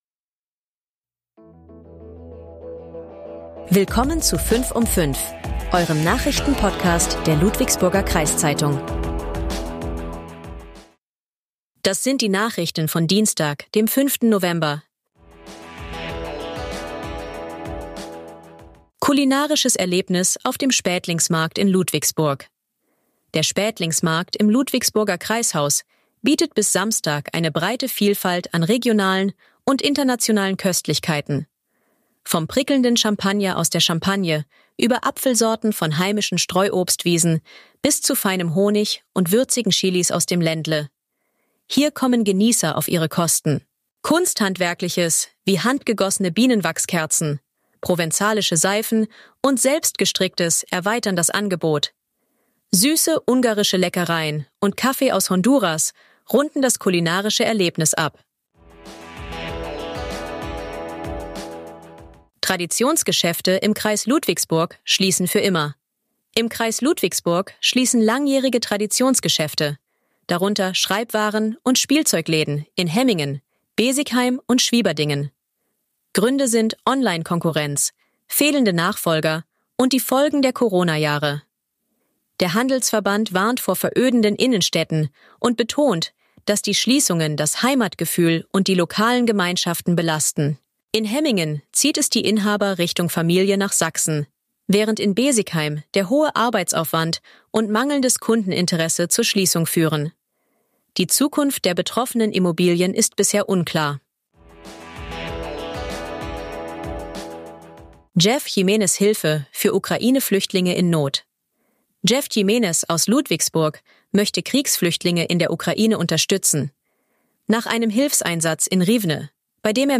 Euer Nachrichten-Podcast der Ludwigsburger Kreiszeitung